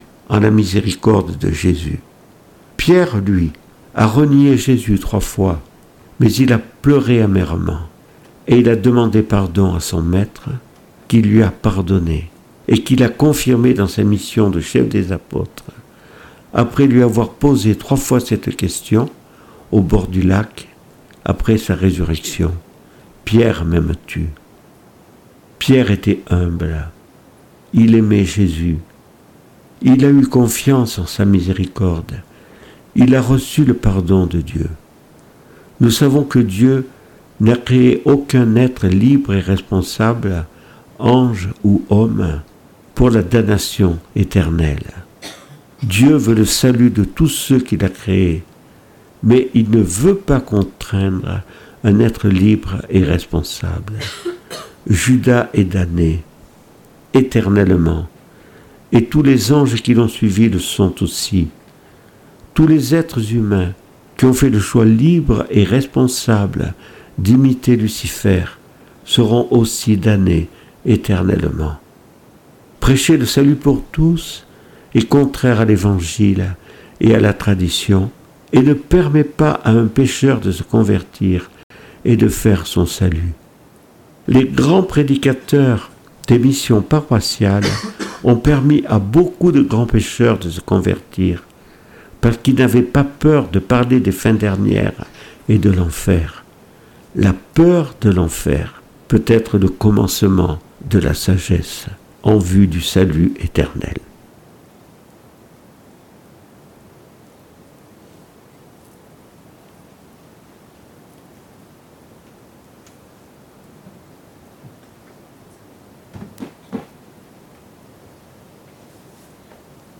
Le chapelet et les vêpres du mardi 31 mars 2026.